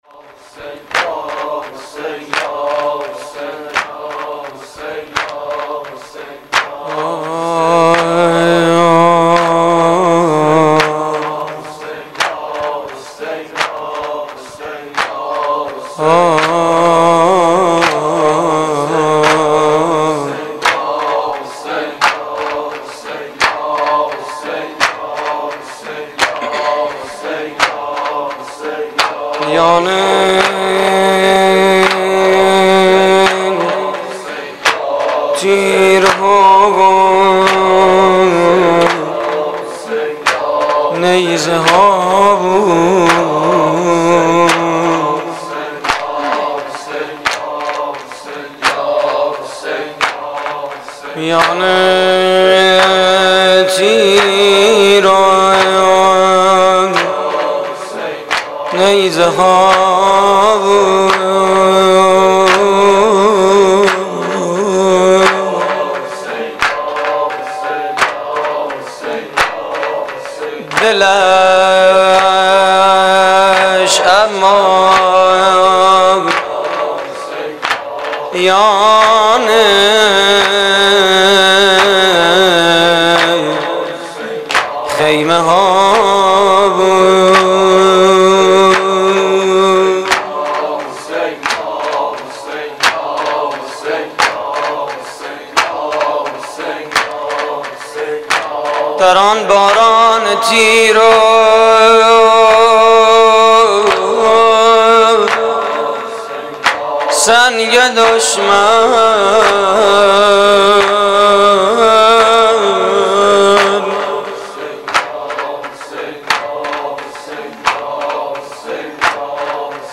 مراسم عزاداری شب پنجم محرم 1432